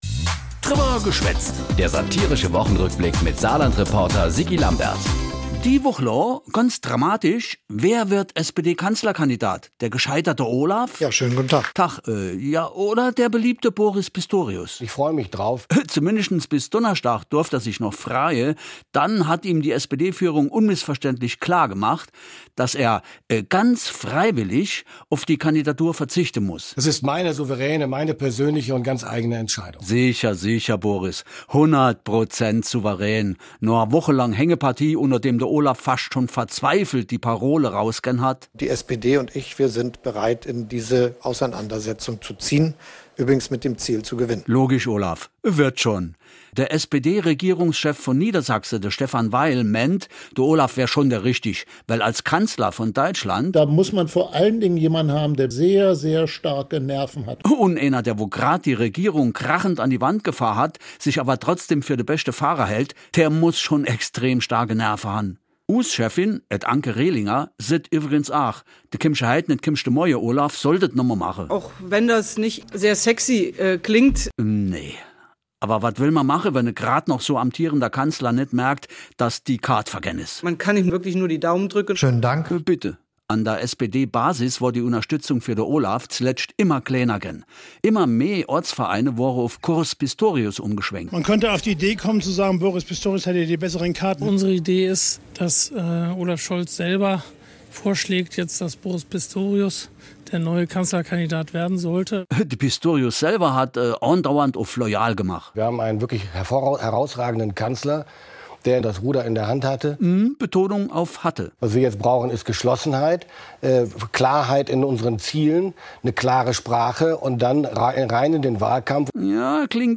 Satirischer Rückblick auf die Ereignisse der Woche jeweils samstags (in Dialekt)